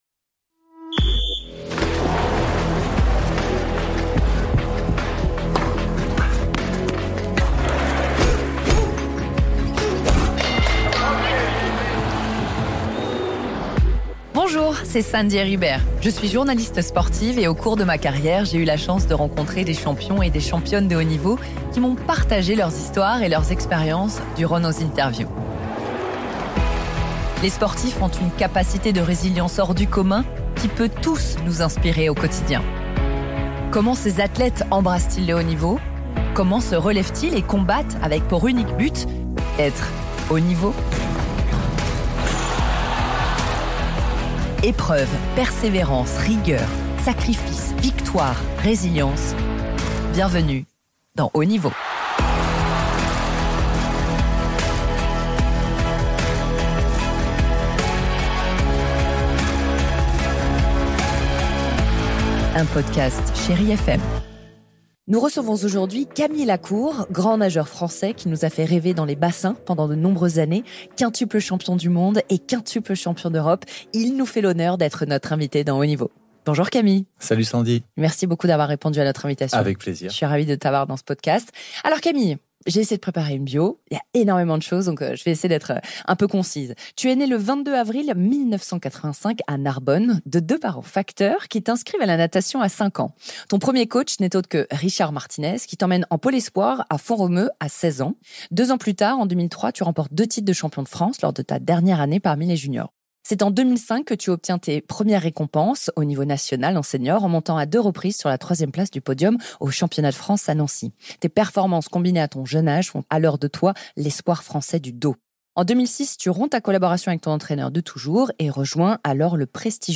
Dans ce troisième épisode, Sandy Heribert reçoit Camille Lacourt.